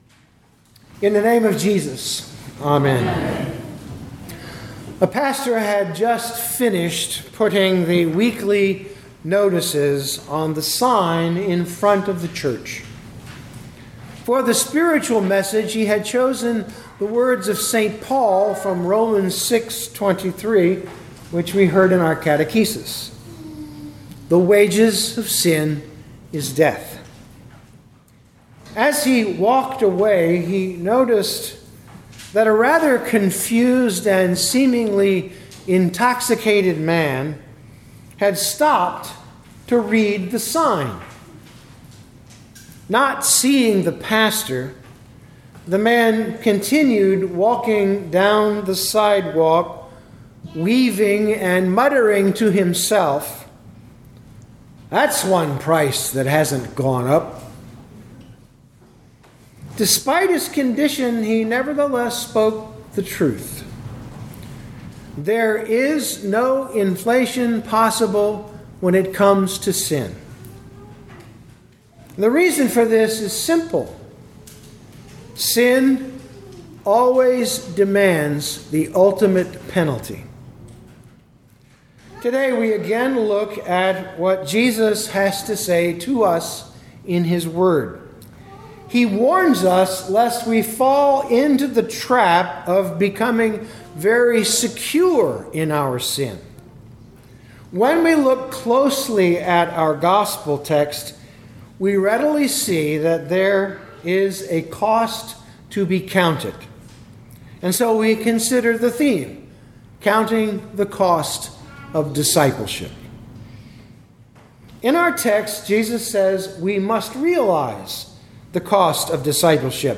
2025 Luke 14:25-35 Listen to the sermon with the player below, or, download the audio.